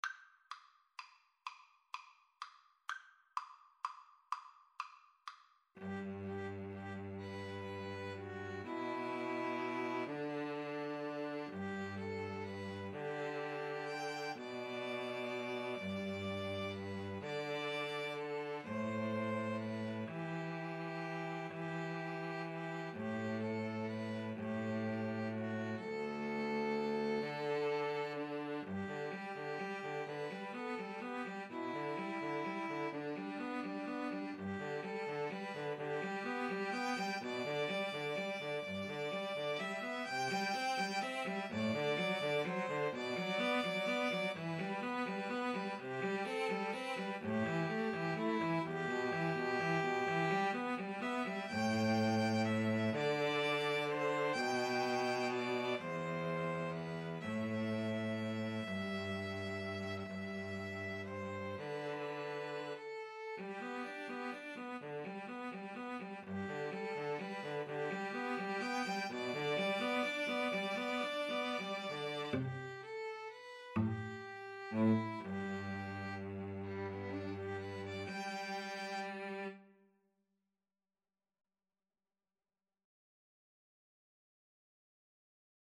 Violin 1Violin 2Cello
G major (Sounding Pitch) (View more G major Music for 2-Violins-Cello )
6/8 (View more 6/8 Music)
Andante ingueno .=42
Classical (View more Classical 2-Violins-Cello Music)
puccini_beloved_father_2VNVC_kar2.mp3